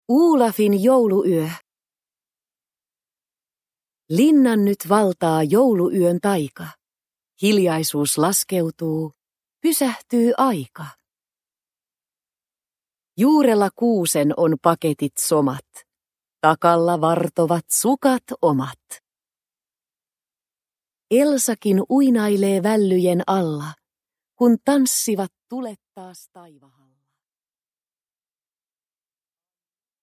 Olafin jouluyö – Ljudbok – Laddas ner